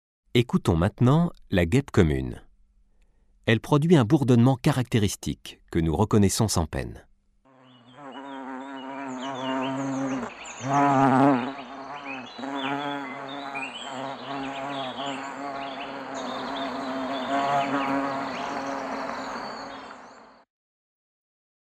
guepe.mp3